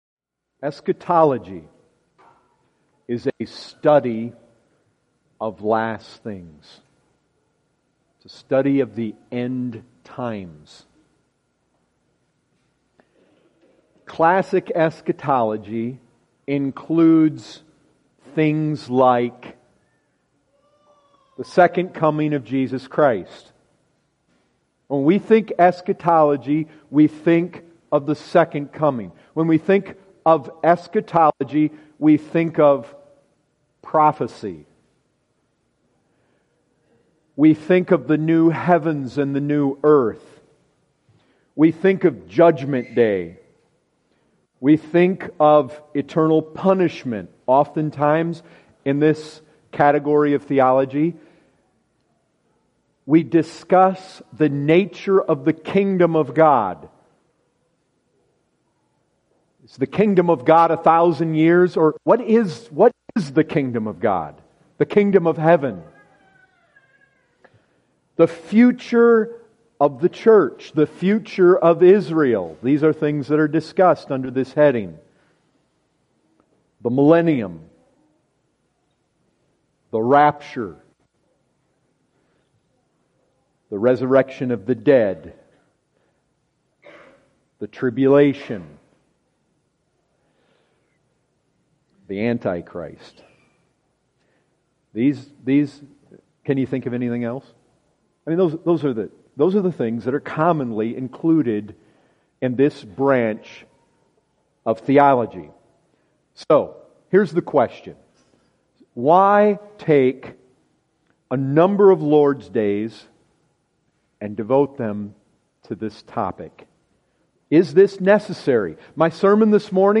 2015 Category: Full Sermons Topic